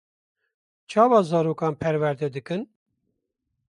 Pronounced as (IPA)
/pɛɾwɛɾˈdɛ/